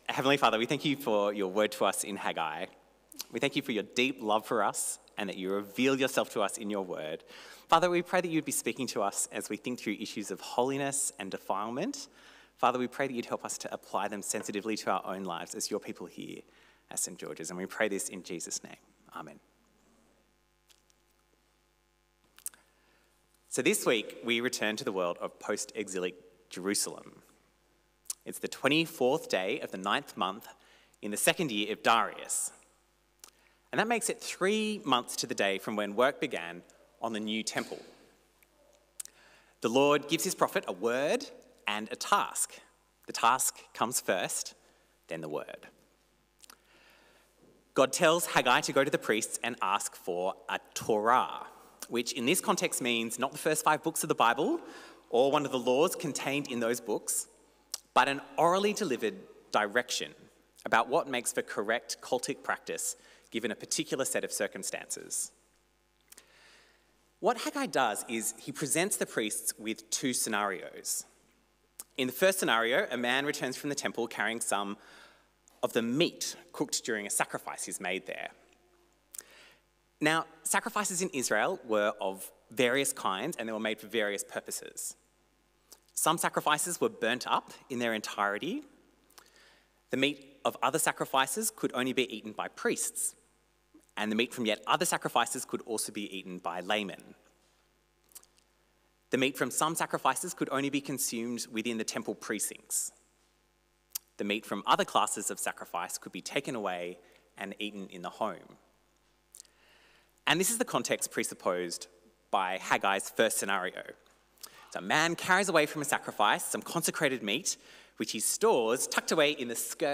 A sermon on Haggai 2:10-19